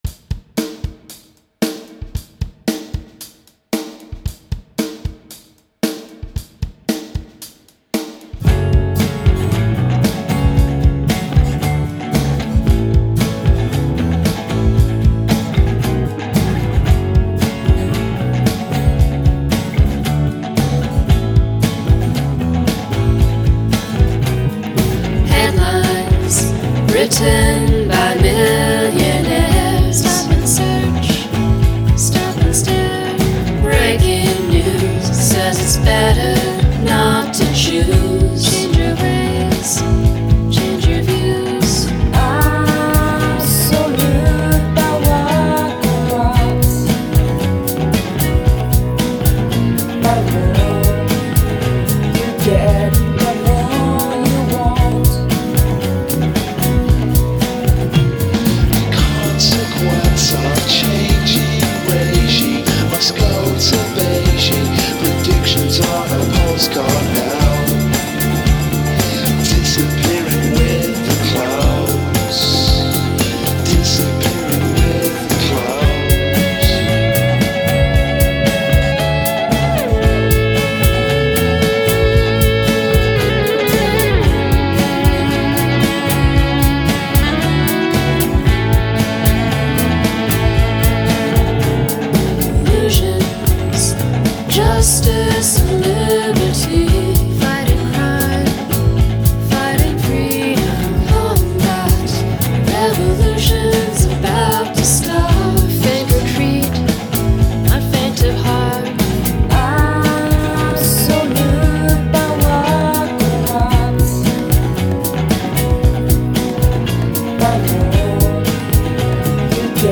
The beginning vocals and harmonies are very nice.